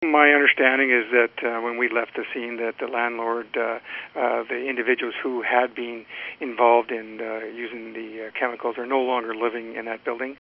Fire Chief Len MacCharles says the product is not connected with the existing tenants.